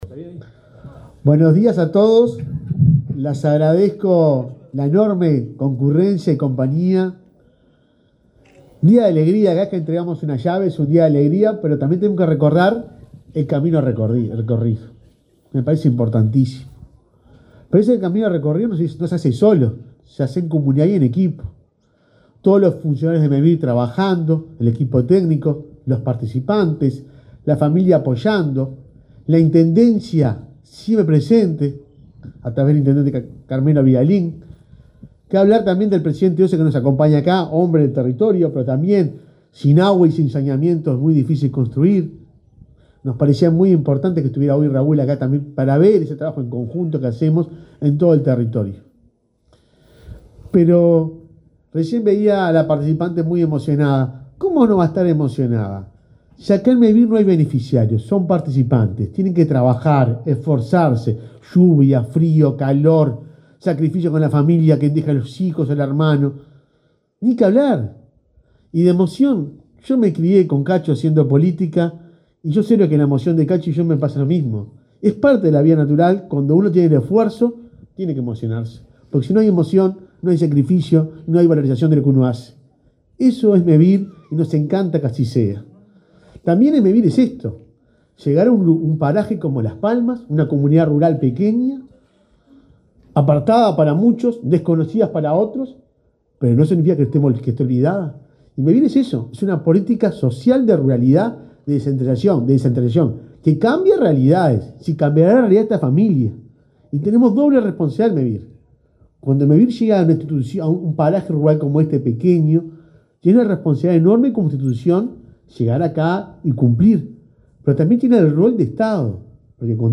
Palabras del presidente de Mevir 15/09/2022 Compartir Facebook X Copiar enlace WhatsApp LinkedIn El presidente de Mevir, Juan Pablo Delgado, encabezó el acto de inauguración de un plan nucleado de viviendas construido por ese organismo, en la localidad de Las Palmas, departamento de Durazno.